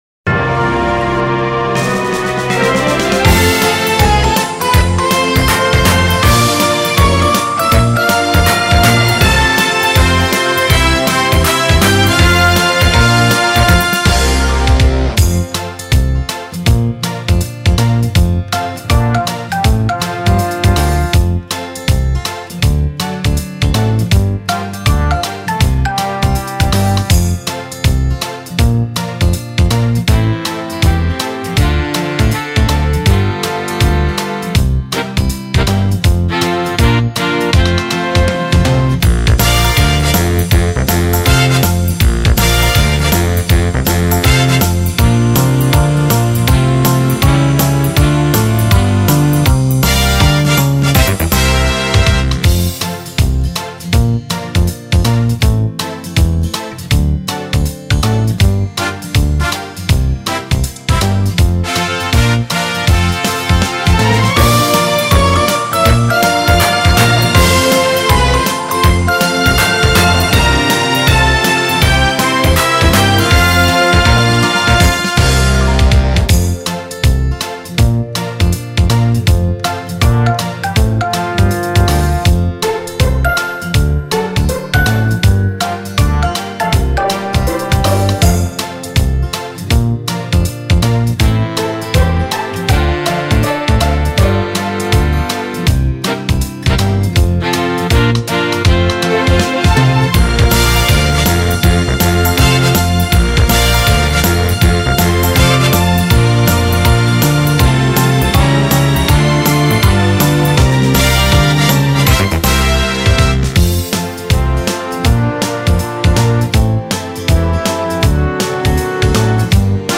歌なし